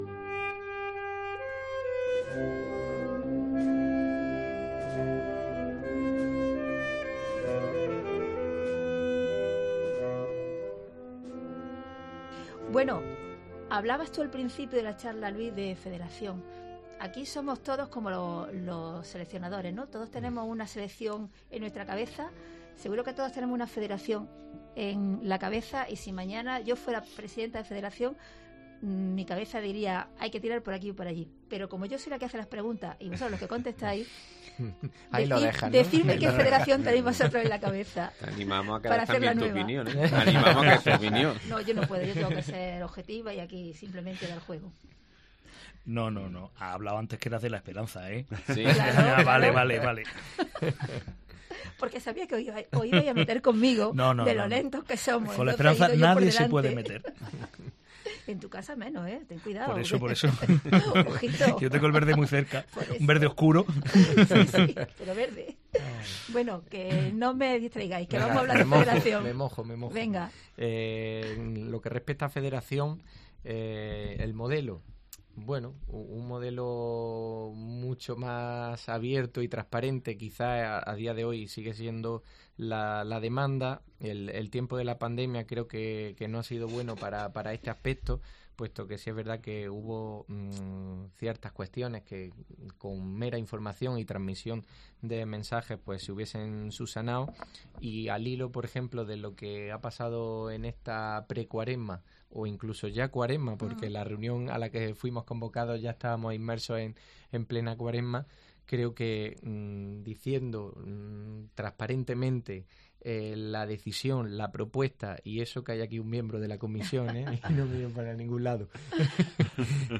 AUDIO: Una charla con cuatro hermanos mayores nos lleva a diseñar cómo podría ser la Semana Santa del futuro.